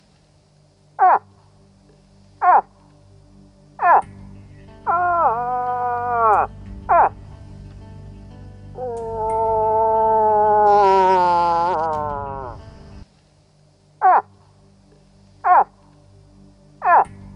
Звуки манка